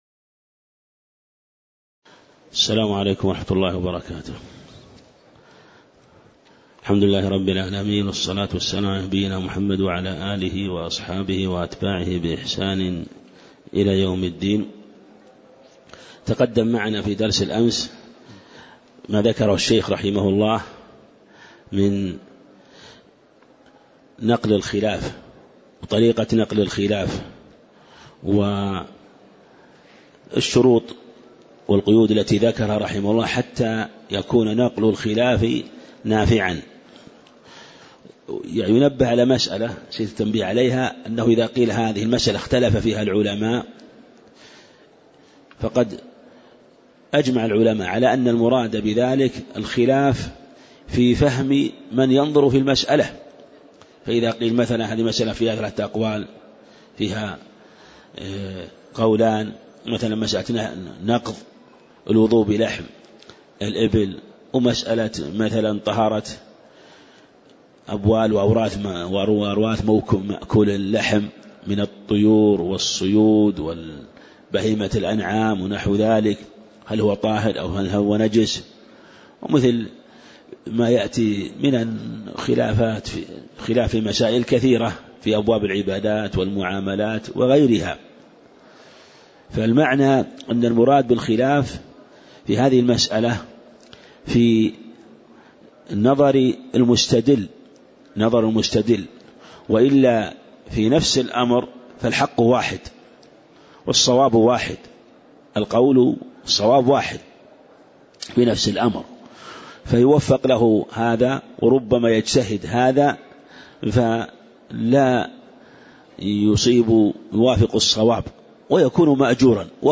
تاريخ النشر ٢٣ شوال ١٤٣٨ هـ المكان: المسجد النبوي الشيخ